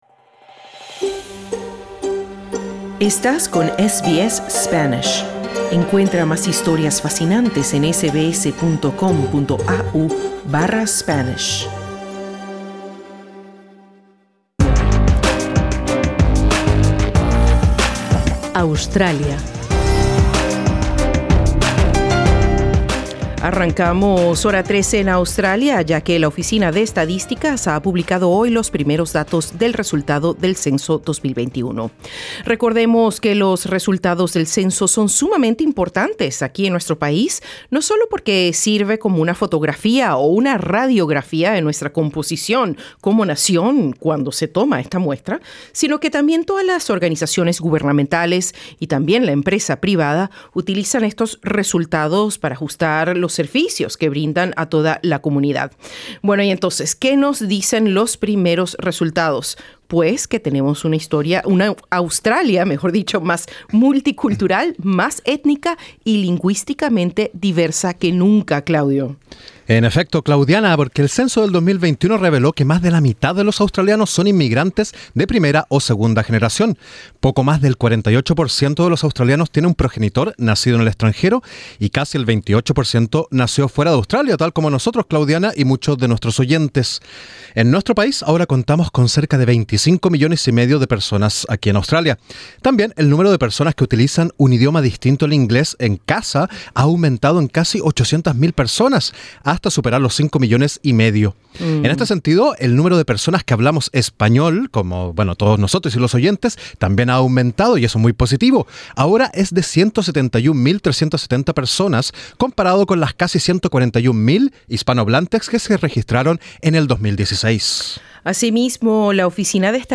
La diversidad cultural y lingüística siguen siendo factores claves en la identidad de Australia, según los datos del último censo poblacional publicados el martes. Para conocer cuáles son las otras características más notables de la sociedad australiana, escucha nuestro informe.